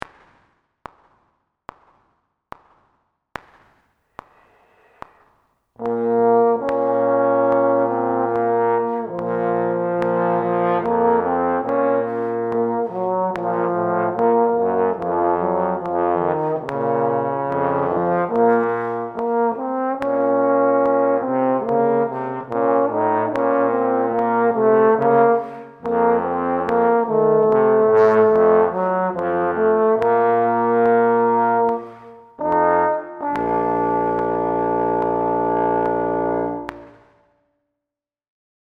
Here’s the audio of the (individual) harmony parts.
Min-10-b-flat.mp3